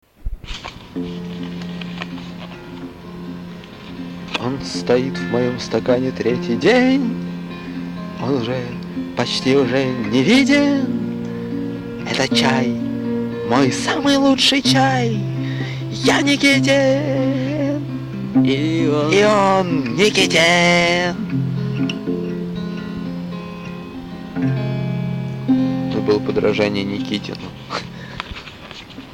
вокал
бэк-вокал
гитара     Обложка